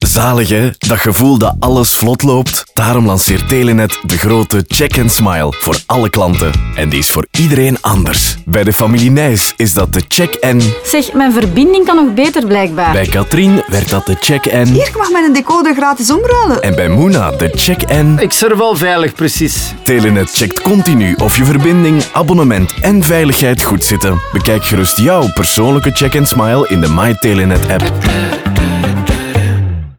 Telenet - Radio.mp3